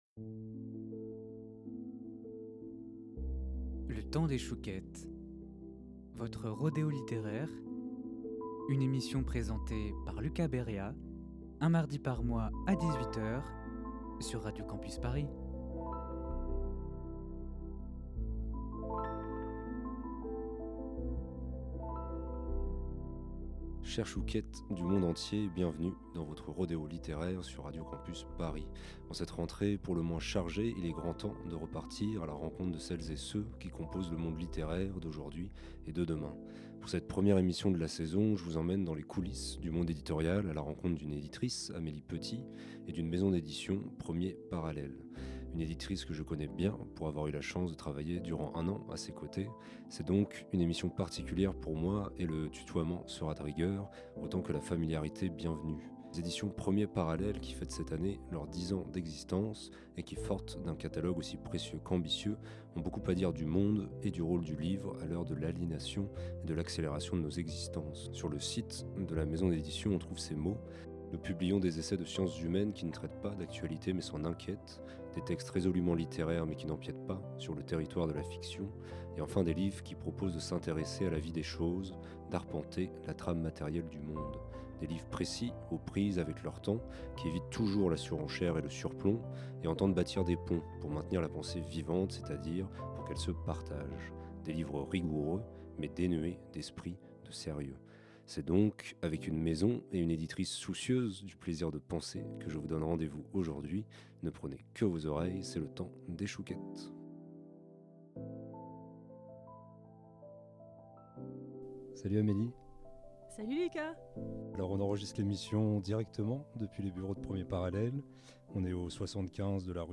C’est donc une émission particulière pour moi et le tutoiement sera de rigueur autant que la familiarité bienvenue.